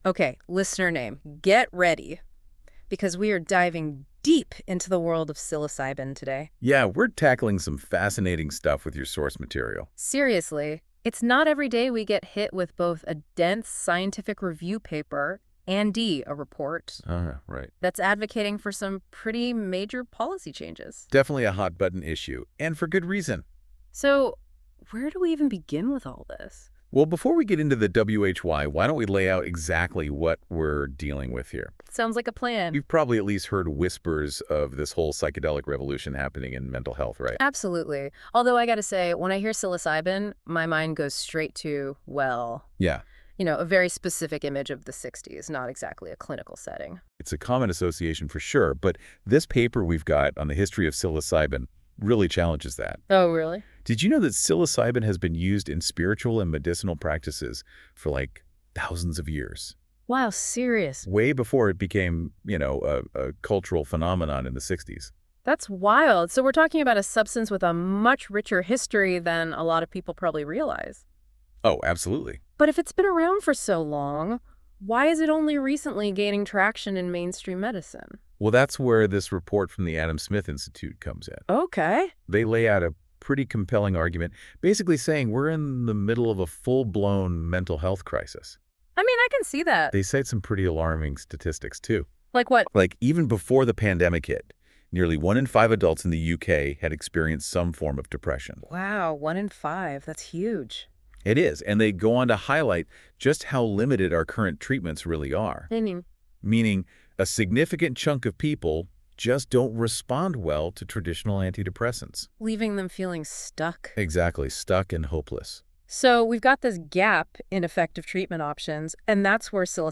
In this episode of The Future Thought Exchange, our AI agents dive deep into the evolving world of psilocybin therapy.